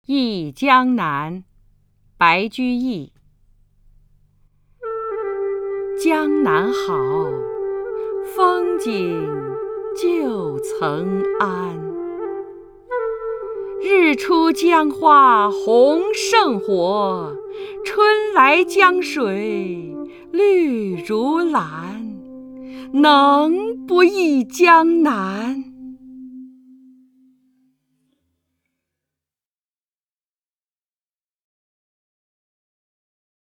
首页 视听 名家朗诵欣赏 张筠英
张筠英朗诵：《忆江南·江南好》(（唐）白居易)　/ （唐）白居易